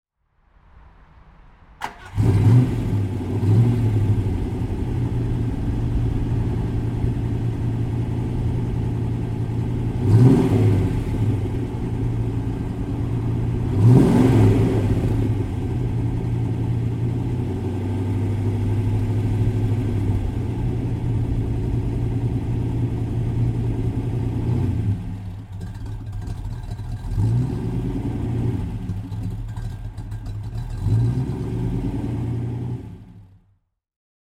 BMW 3200 CS (1964) - Starten und Leerlauf